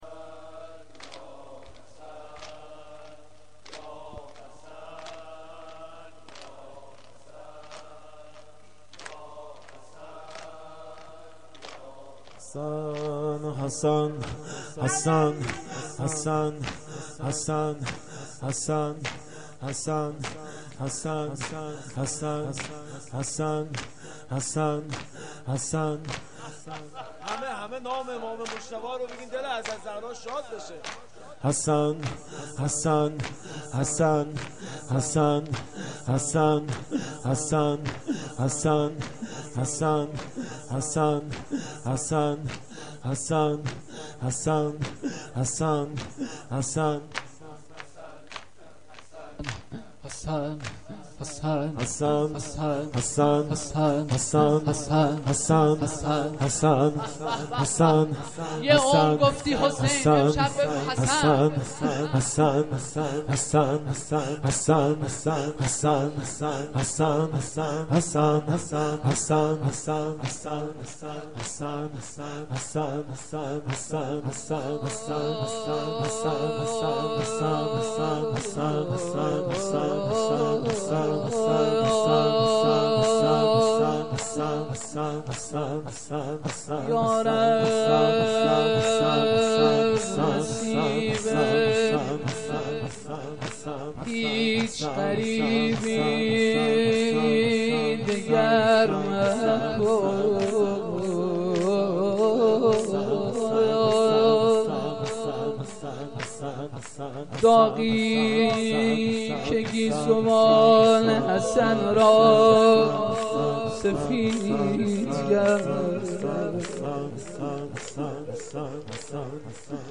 شب سوم مراسم عزای مادر سادات حضرت فاطمه زهرا (س)-فاطمیه اول